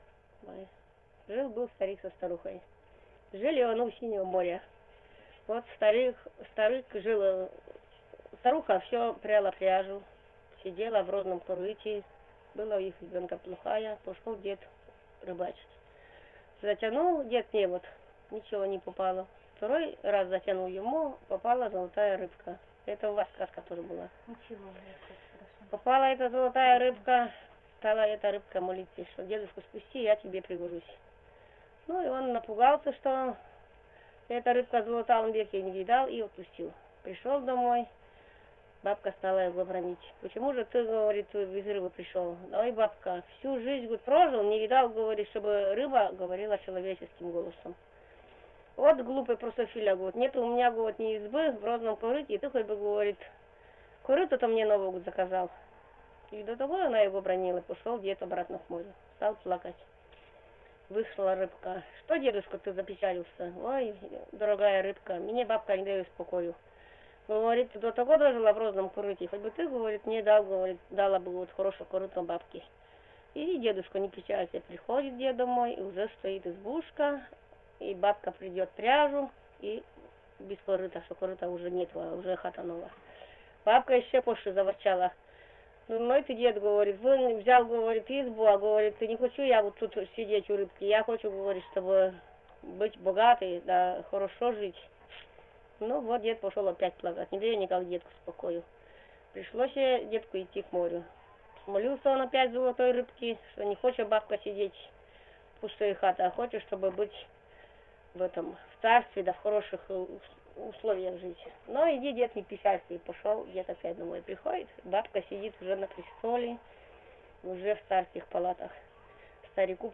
Сказка